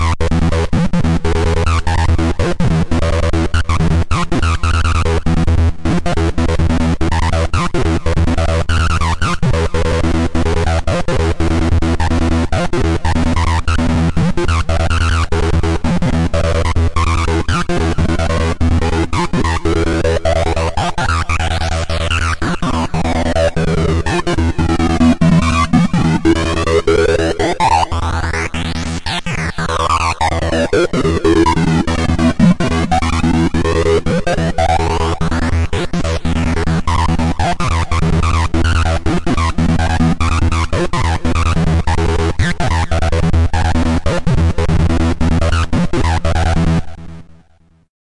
Pro Simple Flute Line 144 BPM Eb Minor
描述：Just a basic flute sounding melody.
标签： 144 bpm Electronic Loops Flute Loops 287.30 KB wav Key : D
声道单声道